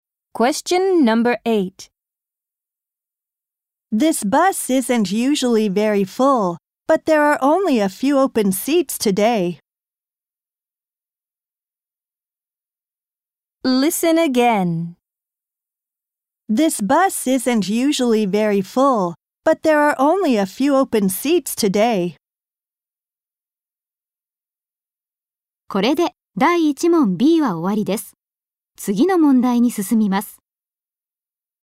○共通テストの出題音声の大半を占める米英の話者の発話に慣れることを第一と考え，音声はアメリカ（北米）英語とイギリス英語で収録。
第1問B 問8 （アメリカ（北米）英語）